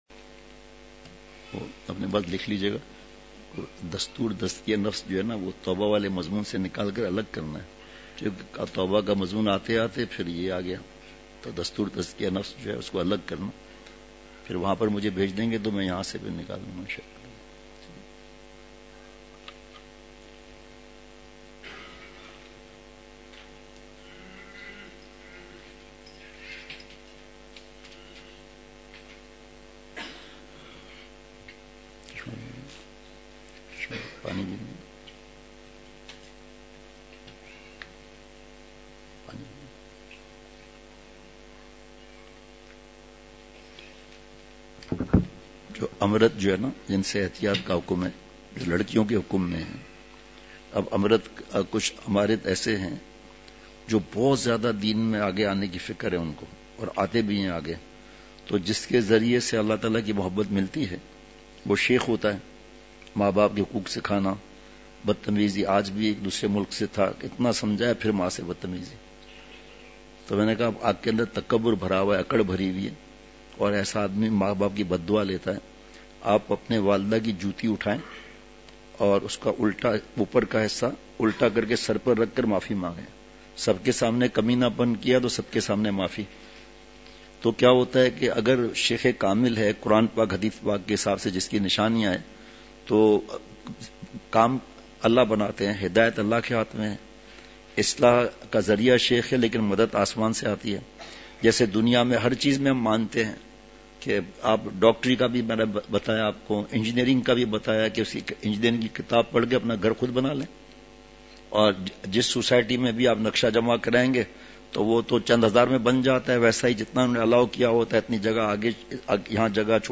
Please download the file: audio/mpeg مجلس محفوظ کیجئے اصلاحی مجلس کی جھلکیاں مقام:مسجد اختر نزد سندھ بلوچ سوسائٹی گلستان جوہر کراچی